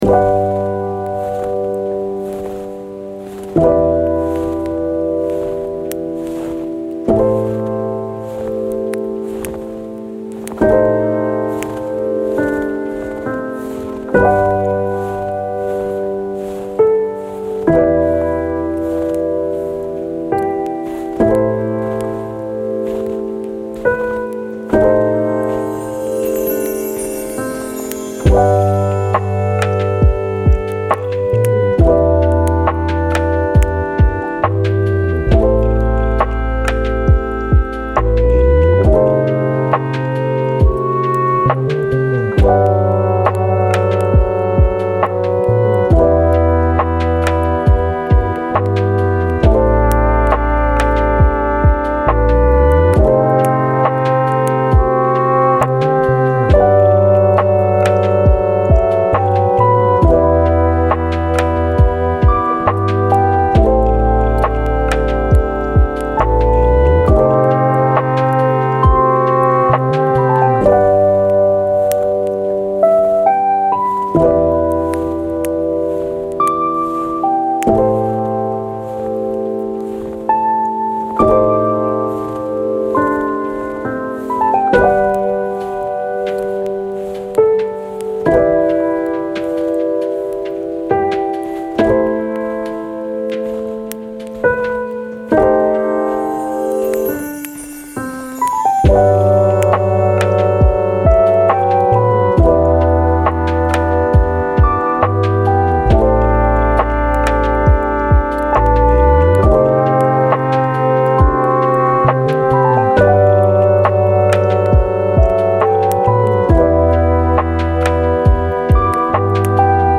Vagues Naturelles : Focus 25 min